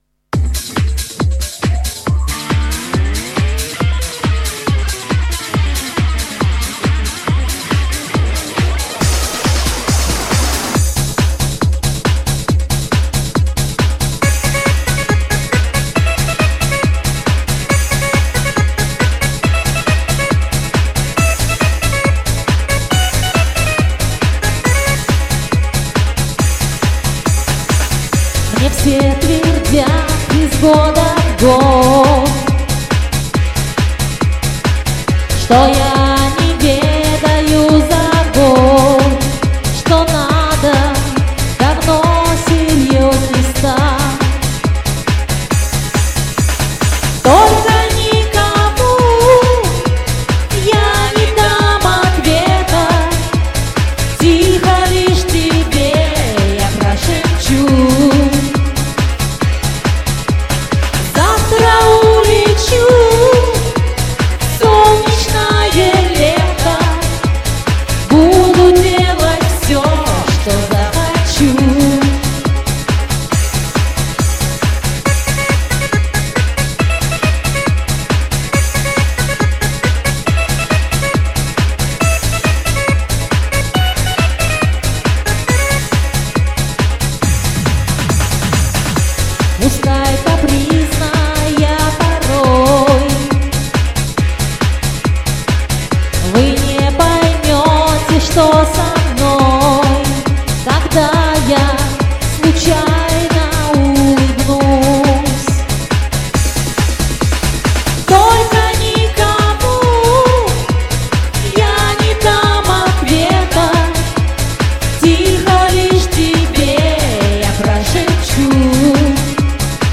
вокализирования  песни во многих  тактах мимо!